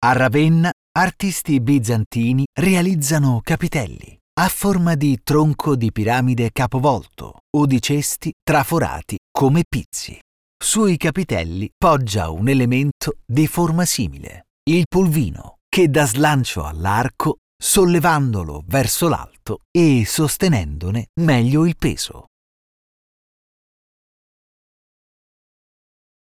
Commerciale, Douce, Corporative, Enjouée, Polyvalente
Guide audio